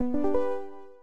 screen_share_started-IZDL-kAw.ogg